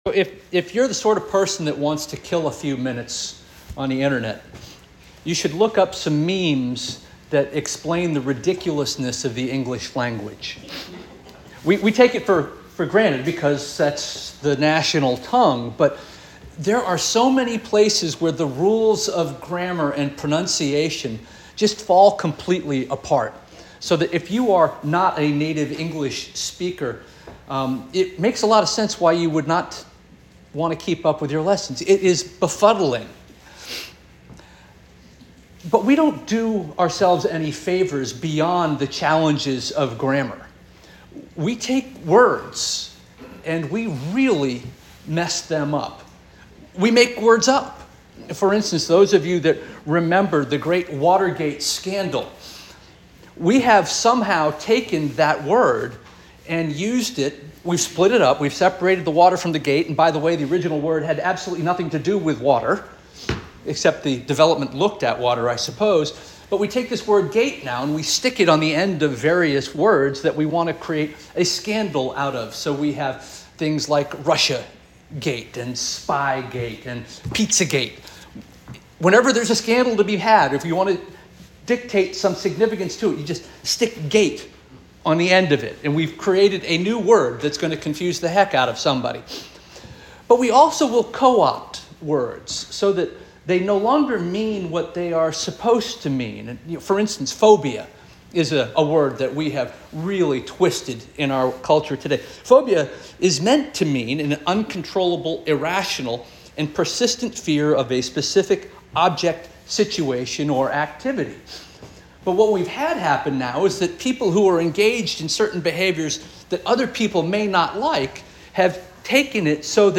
September 15 2024 Sermon - First Union African Baptist Church